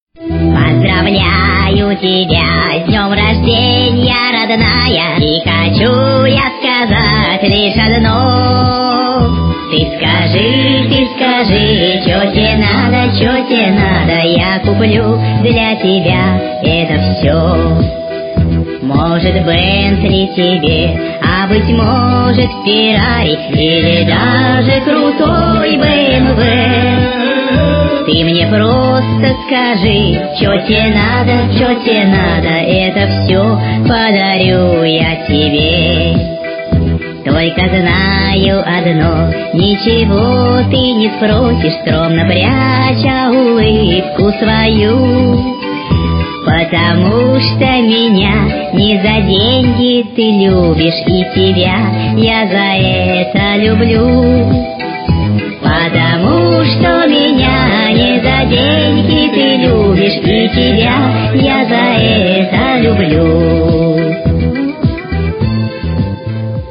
Песня-переделка с днем рождения любимой женщине